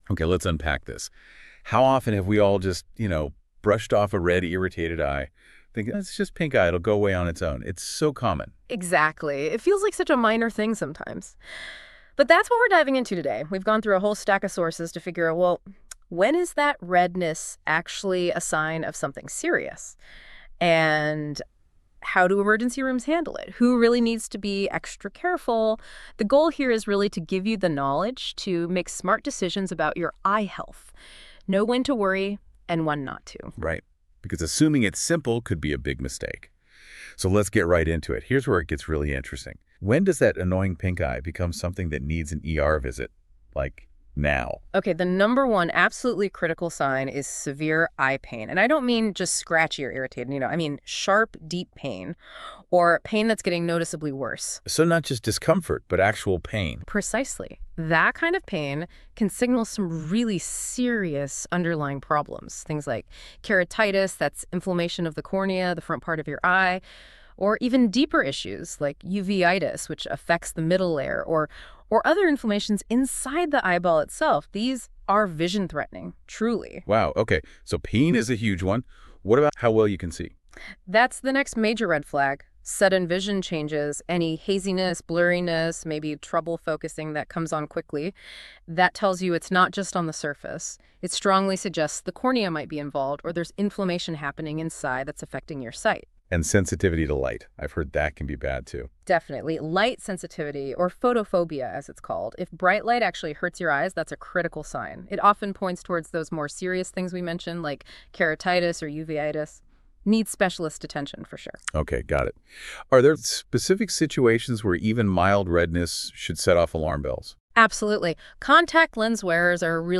Listen to a discussion about when to go to the ER for pink eye When to go to the ER for pink eye?